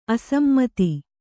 ♪ asammati